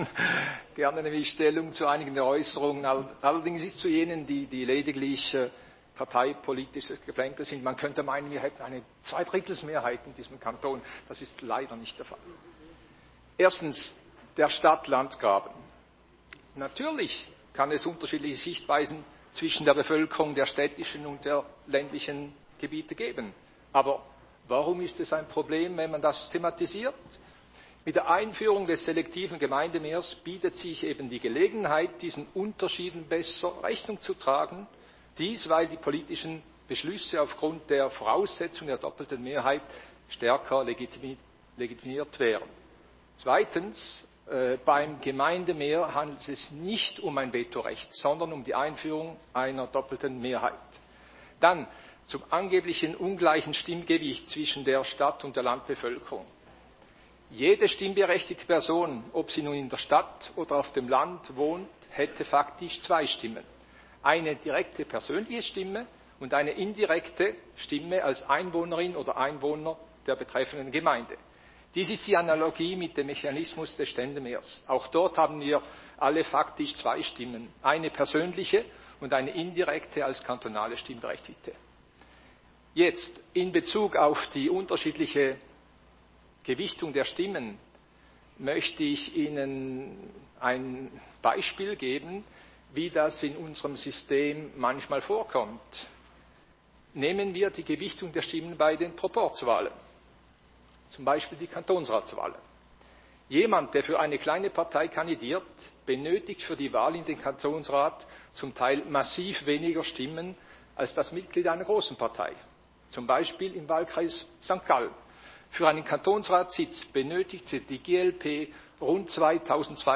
Session des Kantonsrates vom 14. und 15. Februar 2022